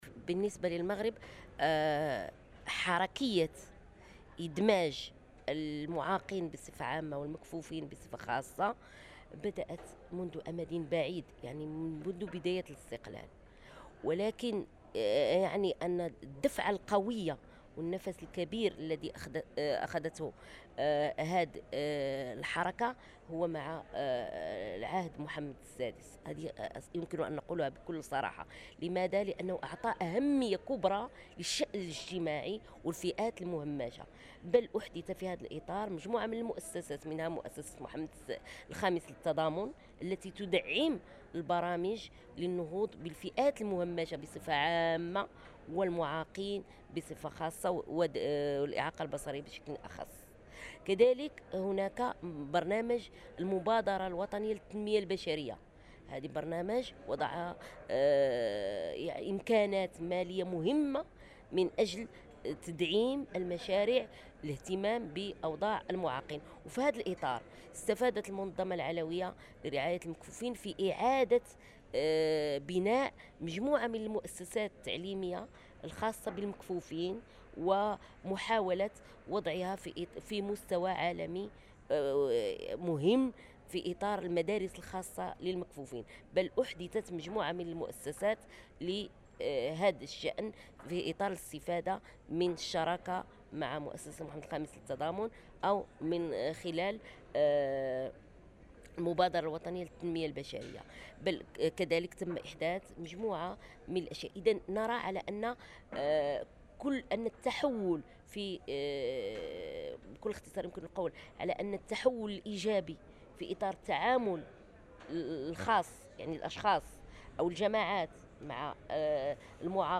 على هامش الجمعية العامة للاتحاد العالمي للمكفوفين المنعقدة في جنيف 15-23 أغسطس 2008.